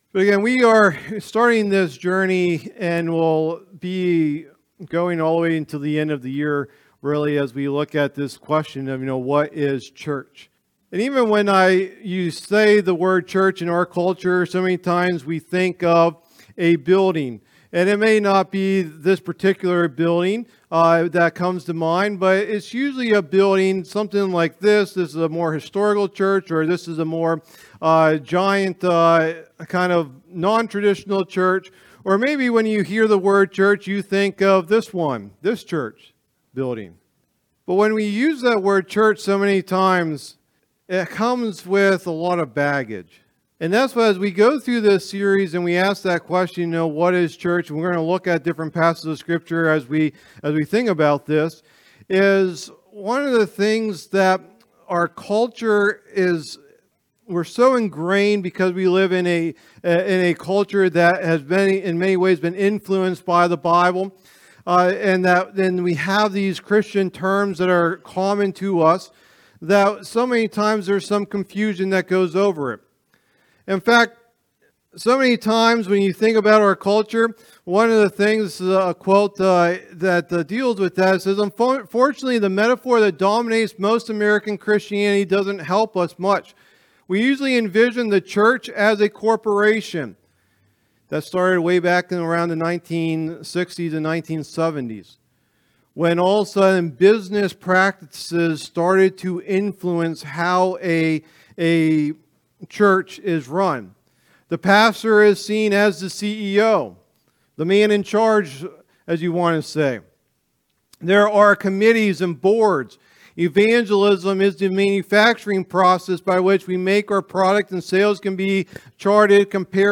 Download Download Reference Matthew 16:13-20 Sermon Notes Matthew 16.13-20.pdf Message #1 in the "What is Church" teaching series What is "Church"?